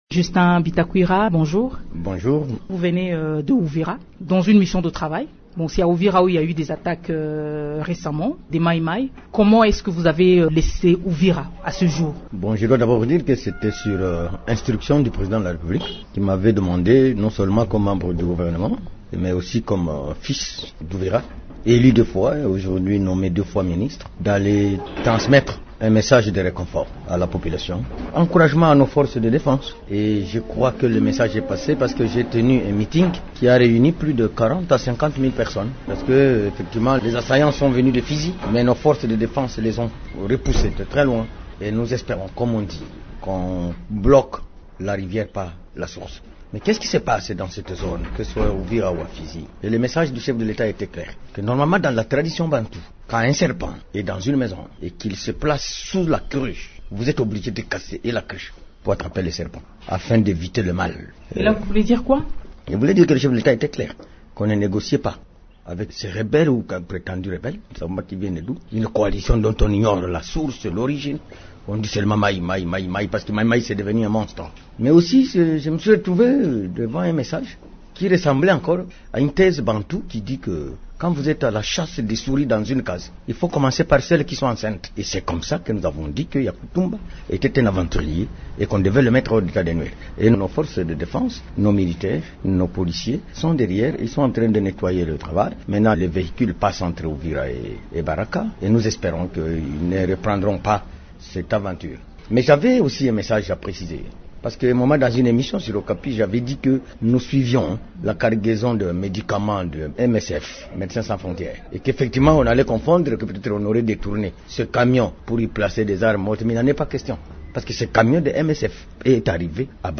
Invité de Radio Okapi lundi 9 octobre, le ministre de Développement Rural, Justin Bitakwira, commente cette situation.